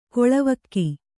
♪ koḷavakki